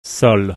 s in sol
s in sat